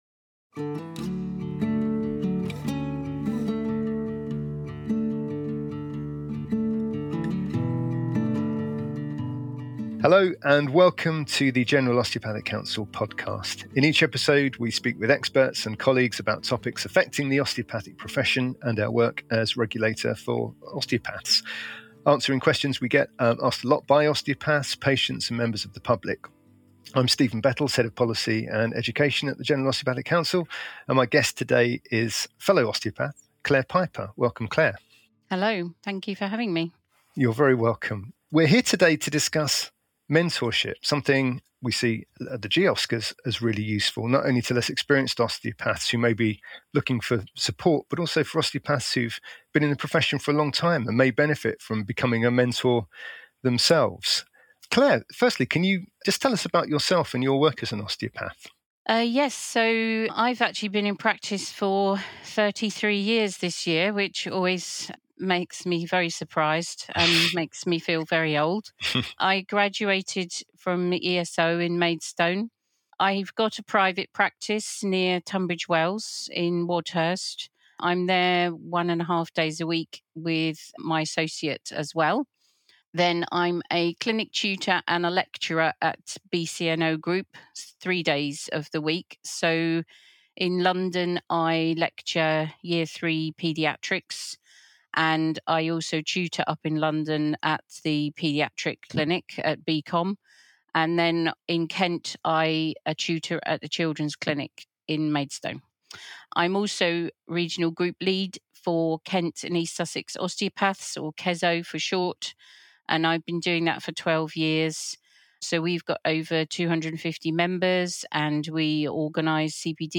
as he speaks with colleagues and experts in the osteopathic profession about issues affecting osteopaths, educators, students and patients. We’ll also share more about our work as a regulator and answer some of the questions we receive from osteopaths, patients and members of the public.